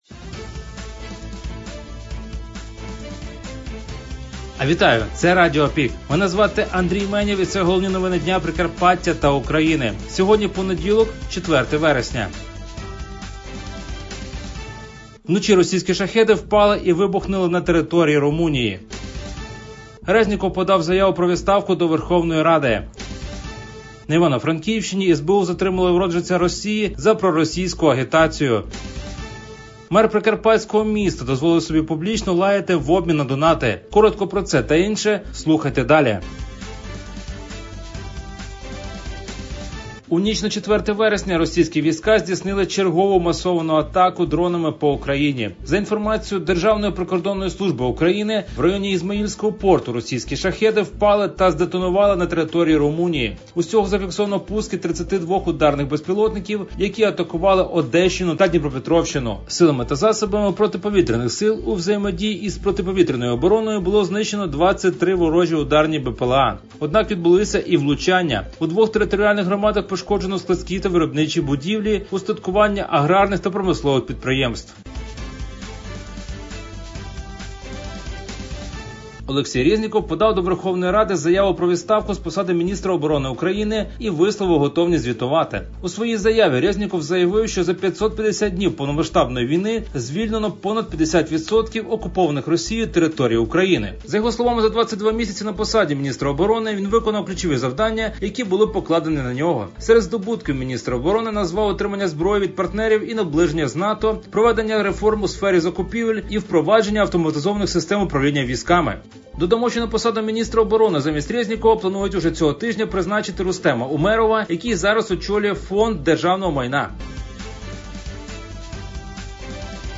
Пропонуємо вам актуальне за день – у радіоформаті.